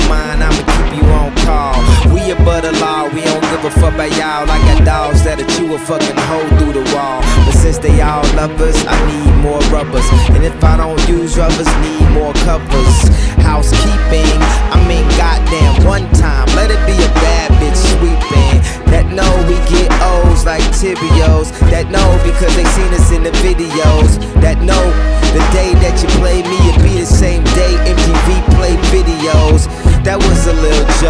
Reduced quality: Yes